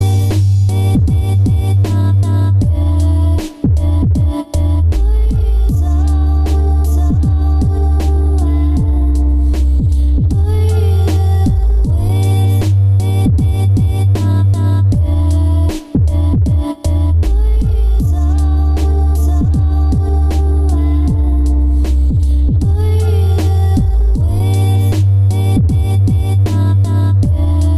• Качество: 320, Stereo
атмосферные
спокойные
Trap
инструментальные
красивый женский голос
beats